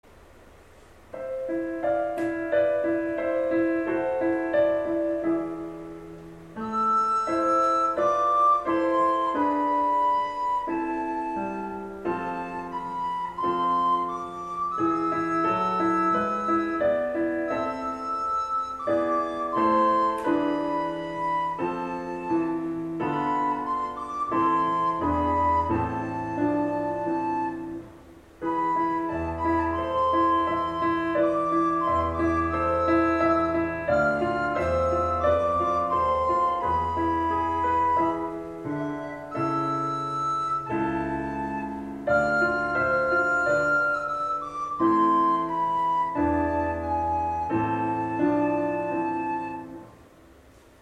5年生のみなさん、今週からリコーダーの学習が始まります。「短調の曲」を２つのパートに分かれて演奏します。
5年小さな約束 リコーダー１